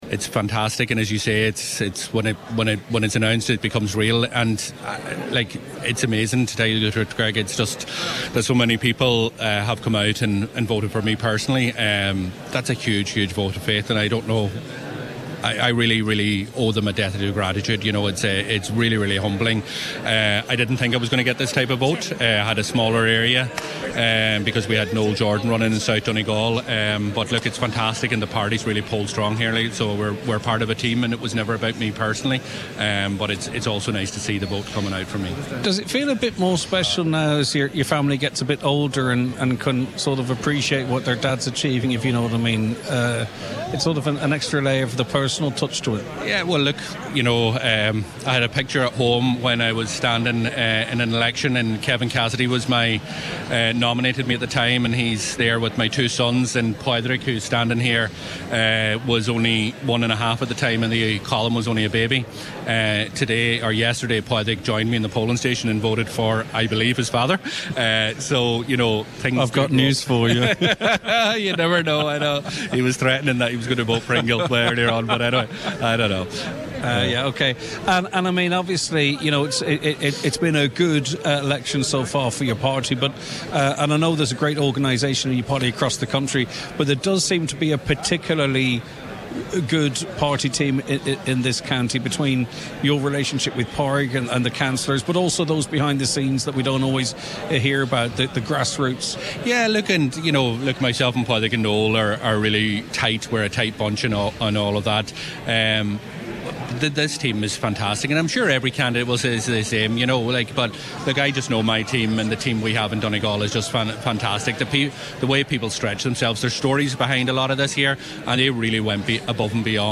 Speaking just after being elected, he says the work starts for him now: